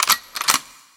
Gun Cockback.wav